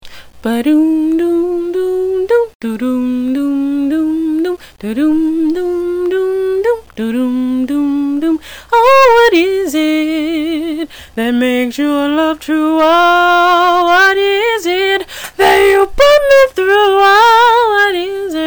I hope that you enjoy the music here all of which is sang without background music mostly intended for those that have insisted that I create this page so that they can hear me sing a bit more.